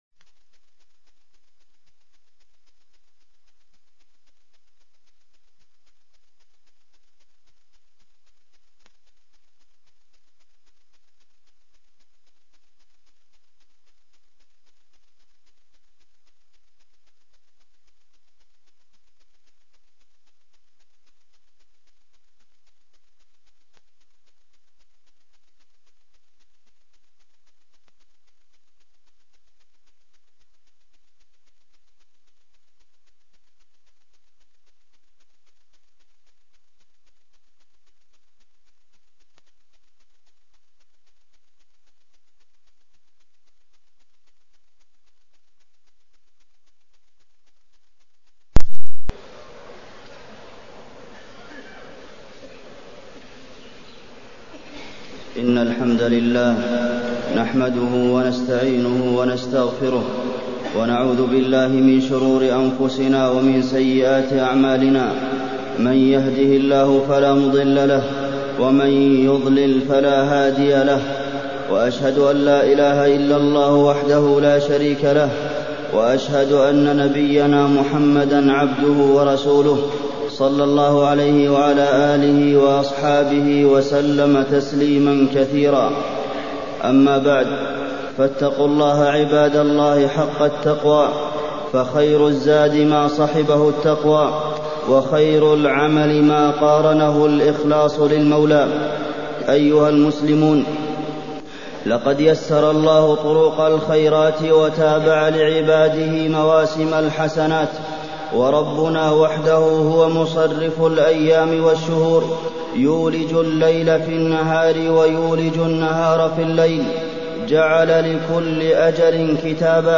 تاريخ النشر ٢٧ رمضان ١٤٢٤ هـ المكان: المسجد النبوي الشيخ: فضيلة الشيخ د. عبدالمحسن بن محمد القاسم فضيلة الشيخ د. عبدالمحسن بن محمد القاسم المداومة على الأعمال